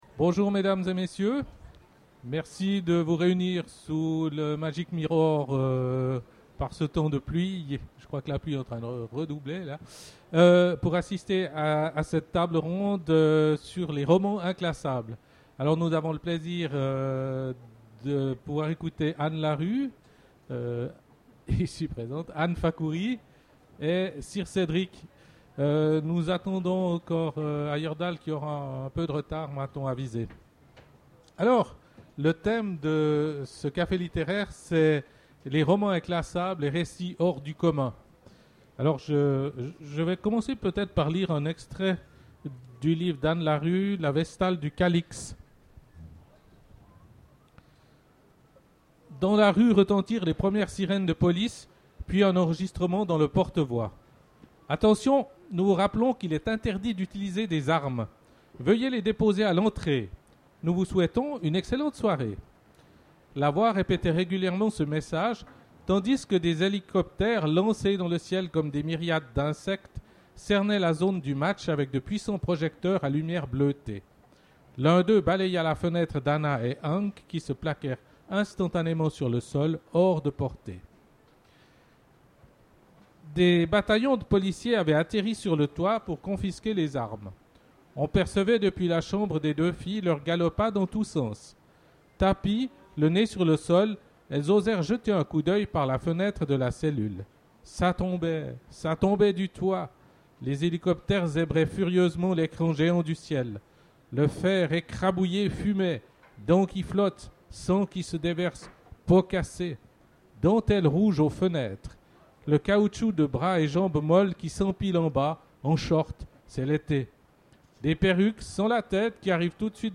Imaginales 2013 : Conférence Romans inclassables...